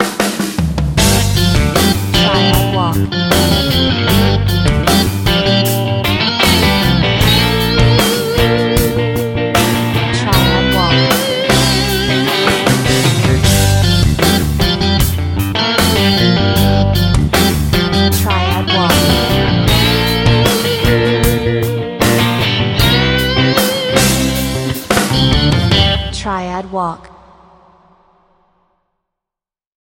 Cool electric guitar music